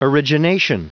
Prononciation du mot origination en anglais (fichier audio)
Prononciation du mot : origination